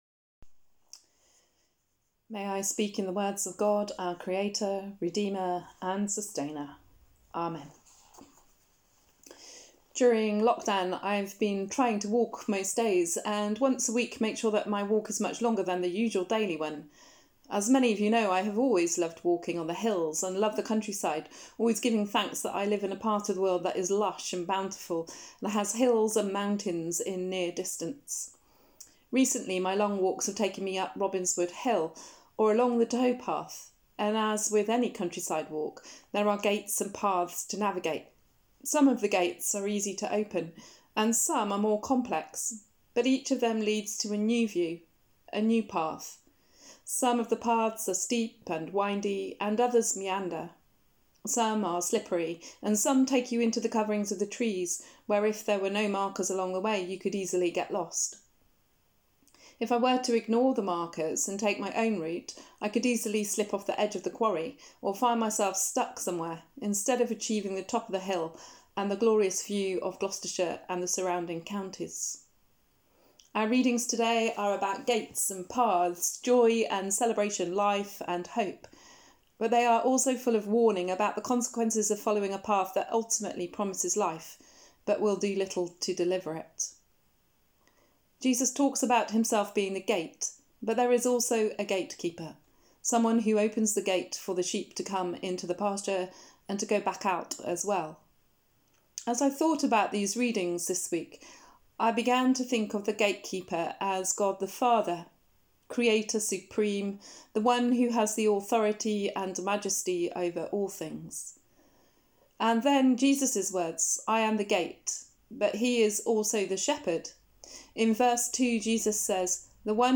Sermon: The Voice of the Shepherd | St Paul + St Stephen Gloucester